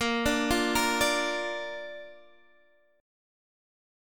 A# chord